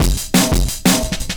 Drop Out 175bpm.wav